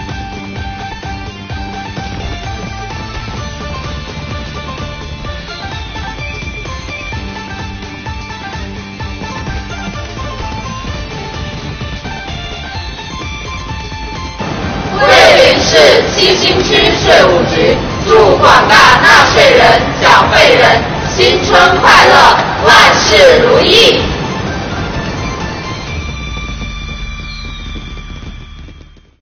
国家税务总局桂林市七星区税务局干部职工为纳税人缴费人送上新春祝福，祝福广大纳税人缴费人新春快乐、万事如意。